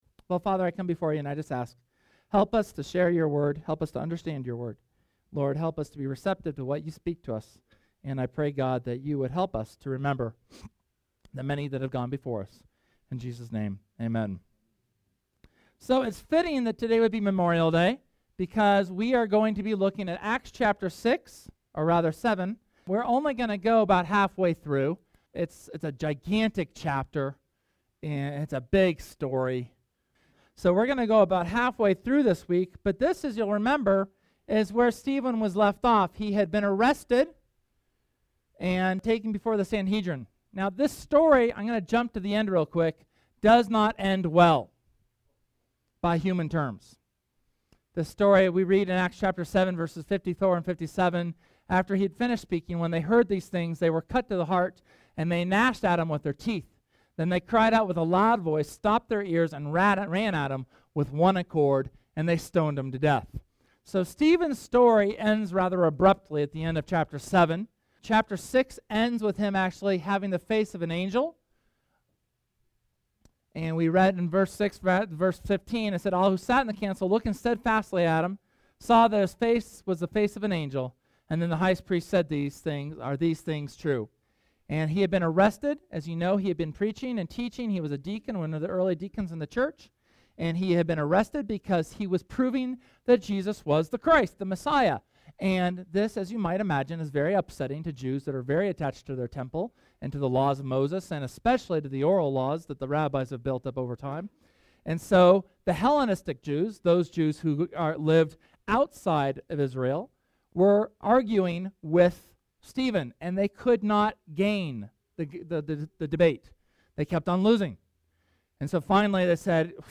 SERMON: Stephen’s Defense pt. 1